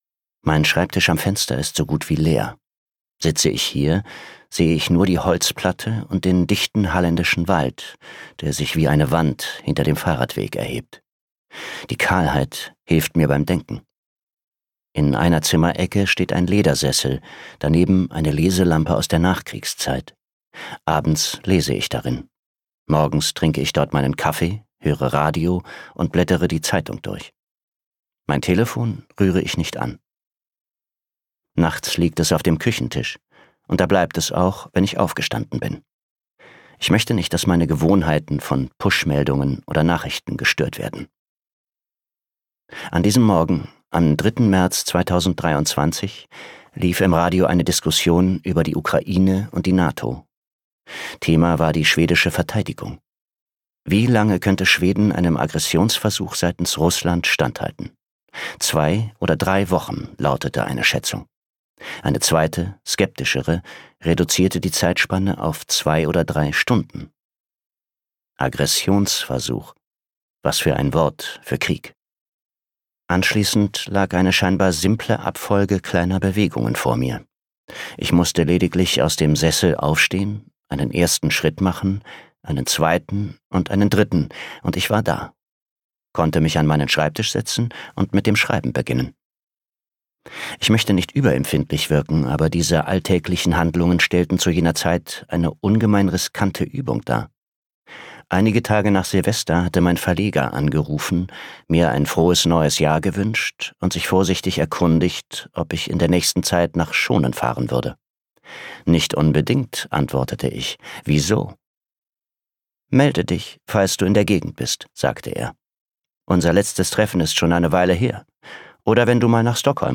Hinter dem Nebel - Christoffer Carlsson | argon hörbuch
Gekürzt Autorisierte, d.h. von Autor:innen und / oder Verlagen freigegebene, bearbeitete Fassung.